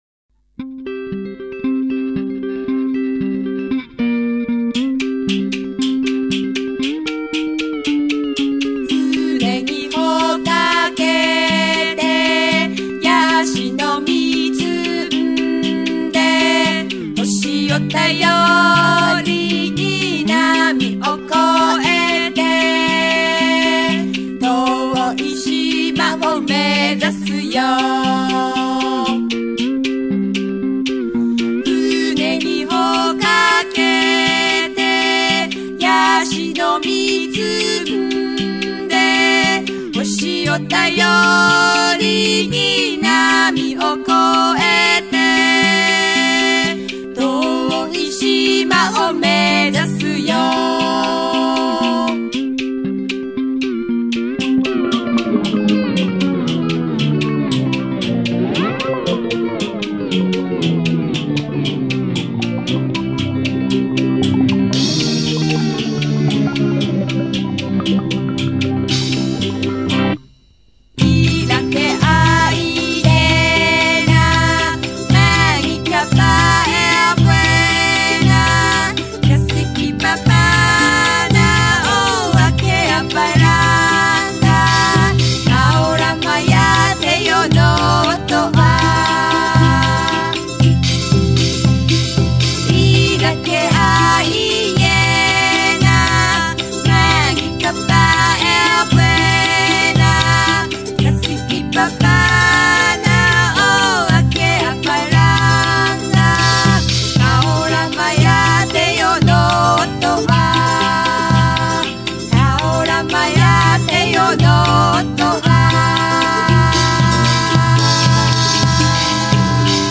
音乐类型：世界音乐
日本 / 冲绳、台湾、太平洋群岛民歌